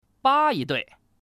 Index of /qixiGame/test/guanDan/goldGame_bak/assets/res/zhuandan/sound/woman/